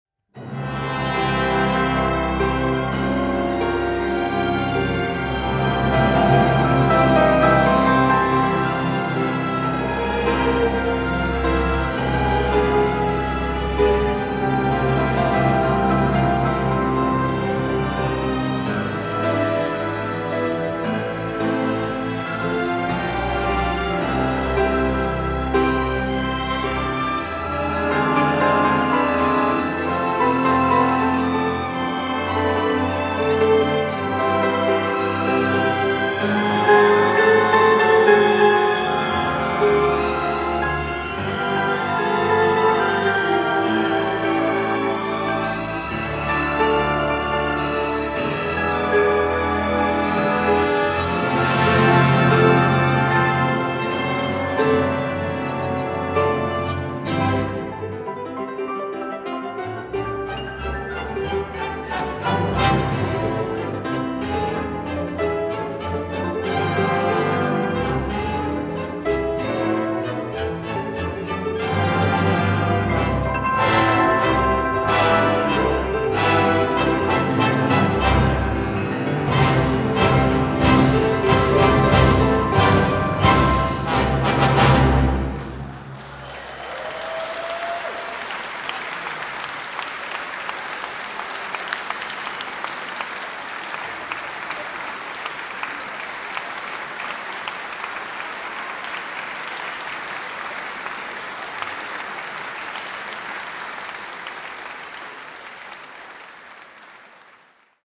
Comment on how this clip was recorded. This evening’s celebration concert